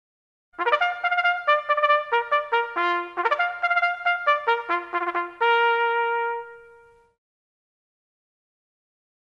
Kategorien Alarm